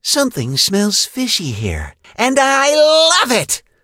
kit_lead_vo_04.ogg